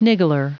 Prononciation du mot niggler en anglais (fichier audio)
Prononciation du mot : niggler